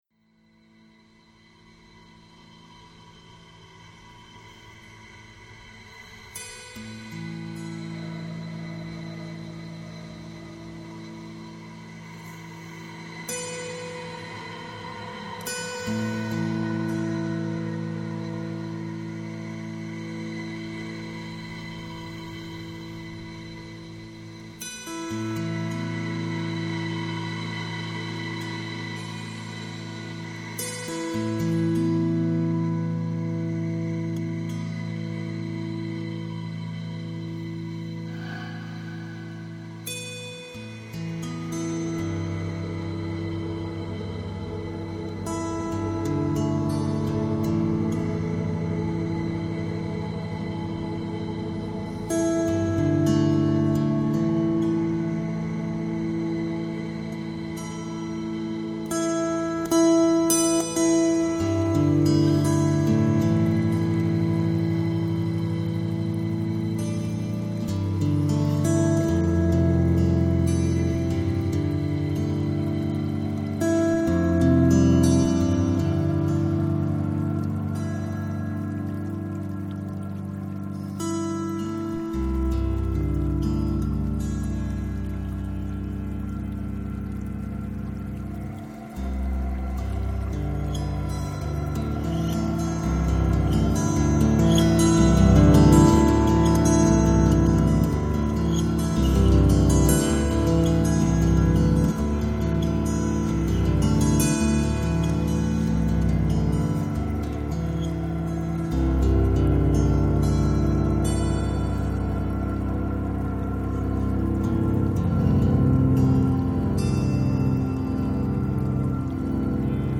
experimental music duo
kantele
39-string electric kantele